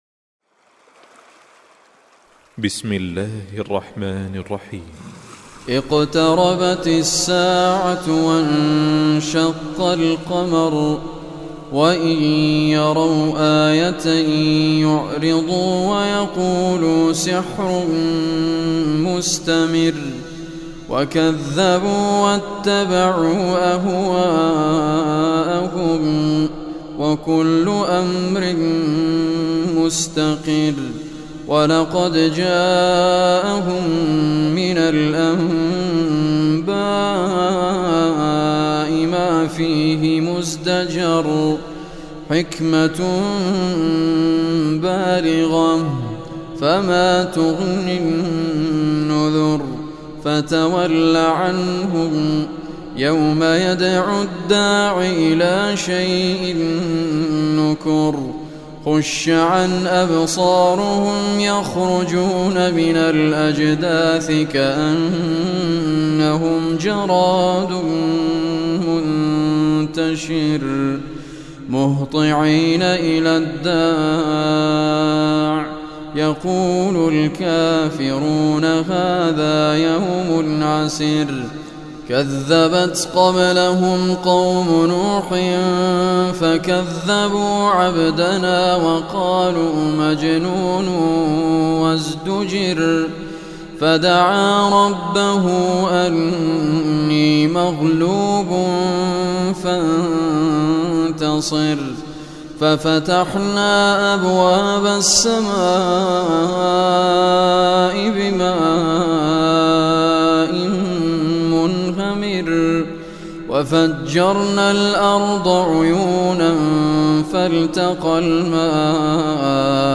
Surah Qamar Recitation by Sheikh Raad Kurdi
Surah Qamar, listen or play online mp3 tilawat / recitation in Arabic in the beautiful voice of Sheikh Raad al Kurdi.